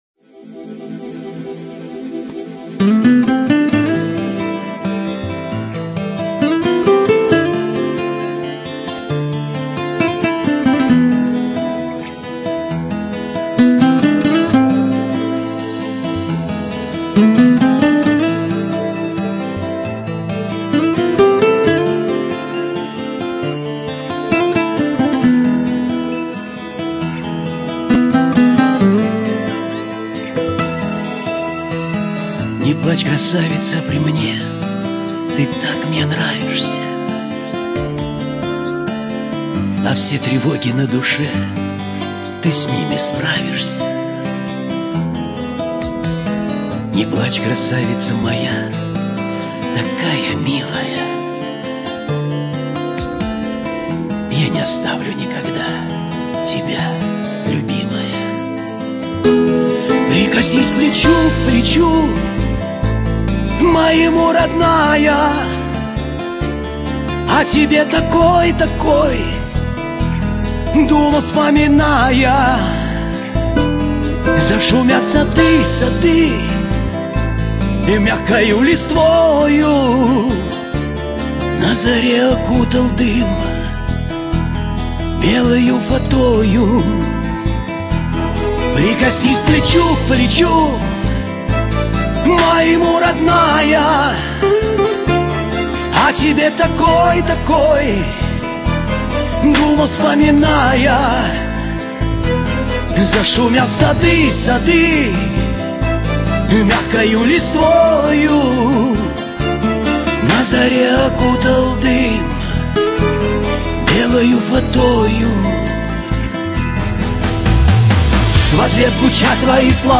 музыка шансон